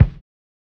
LO FI 10 BD.wav